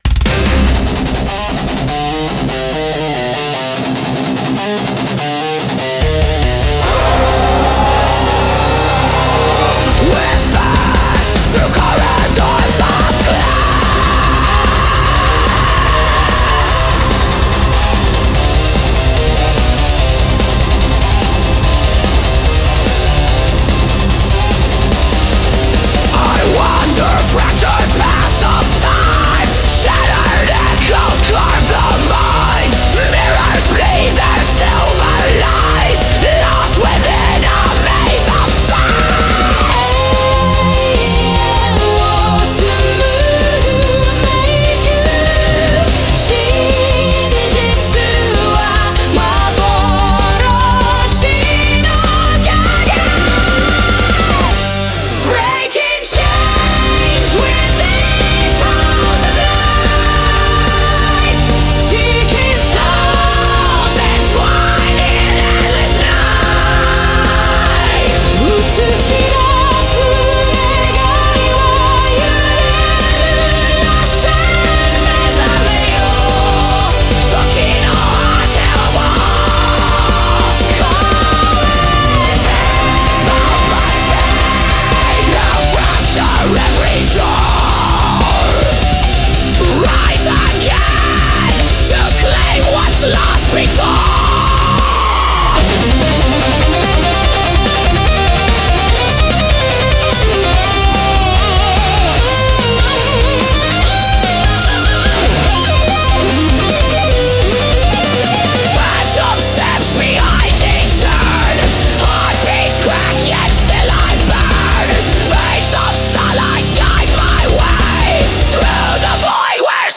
Melodic Death Metal